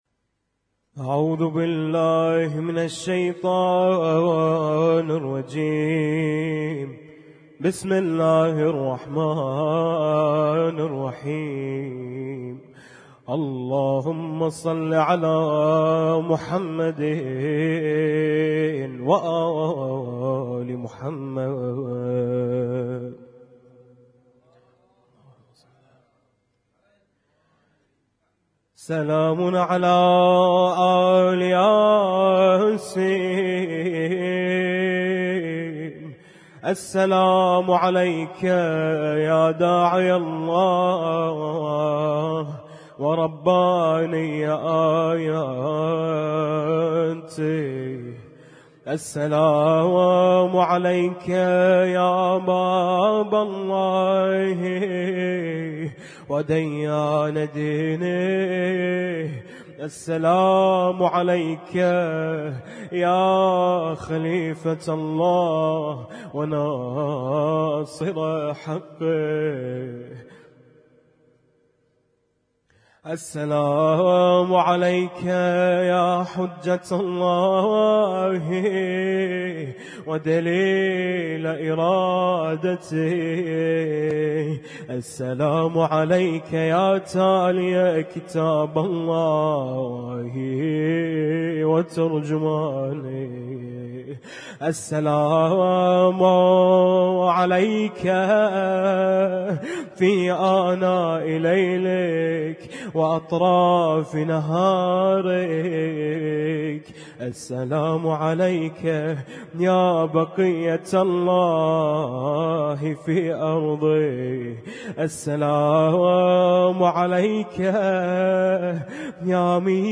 القارئ: الرادود